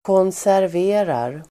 Uttal: [kånsärv'e:rar]